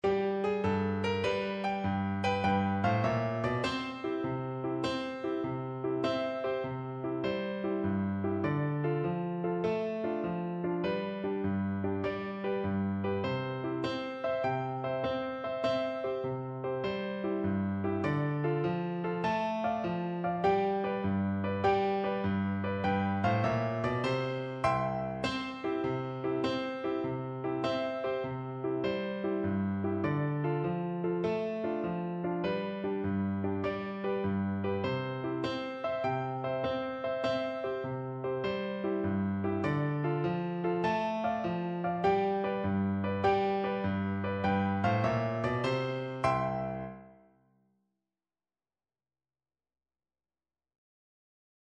6/8 (View more 6/8 Music)
G5-A6
Allegro .=c.100 (View more music marked Allegro)
Traditional (View more Traditional Flute Music)